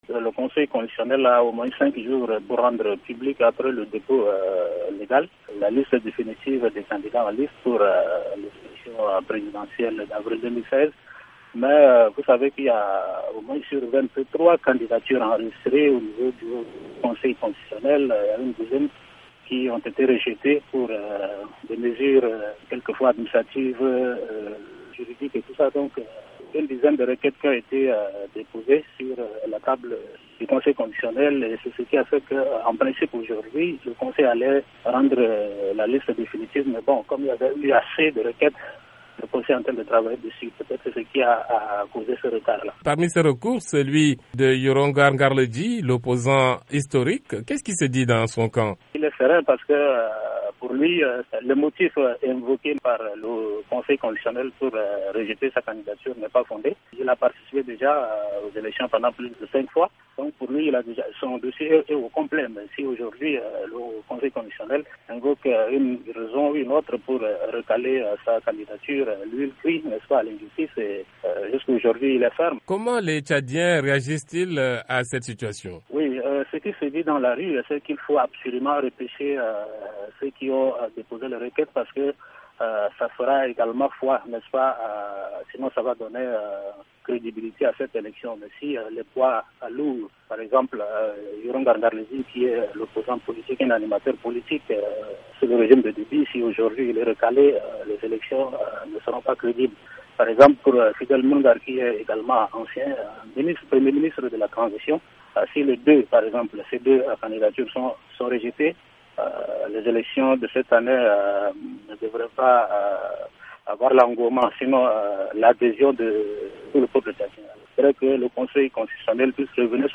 Notre correspondant à N’Djamena